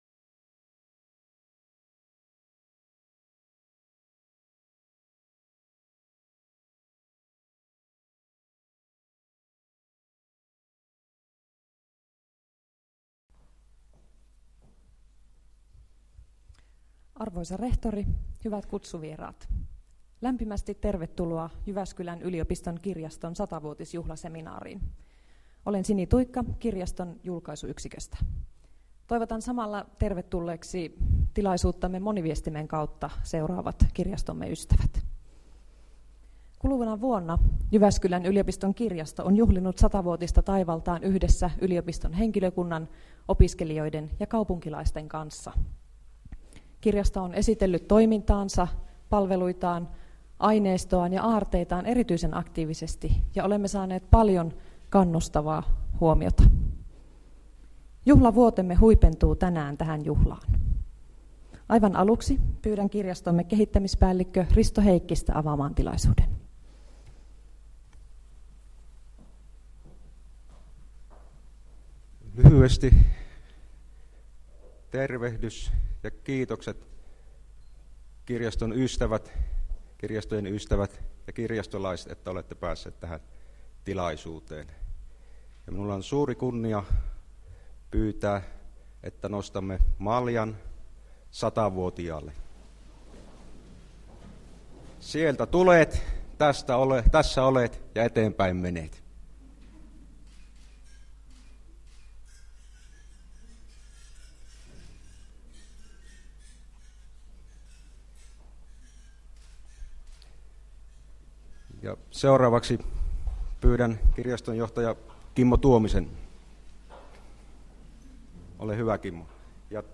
Kirjasto 100v - juhlatilaisuus 16.11.2012